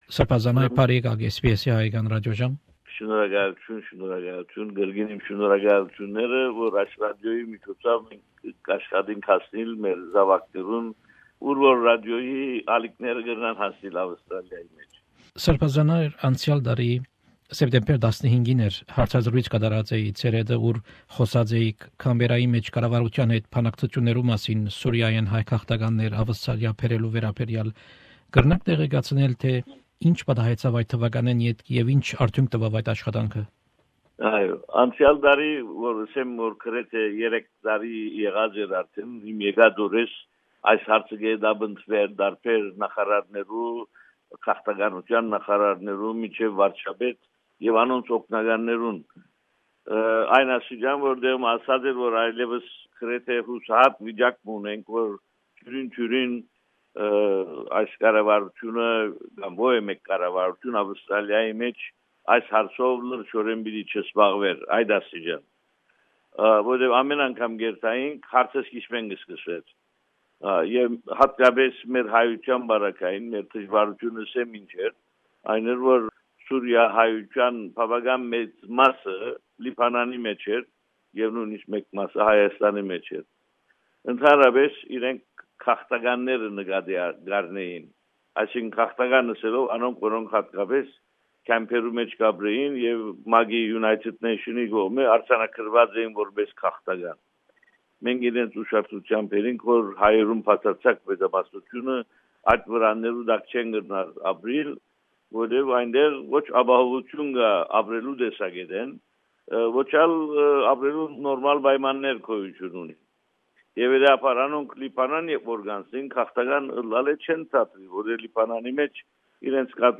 Interview with His Grace Bishop Haygazoun Najarian, Primate of Armenians in Australia & New Zealand about Armenian refugees migrating from Syria to Australia and the importance of filling the census form correctly.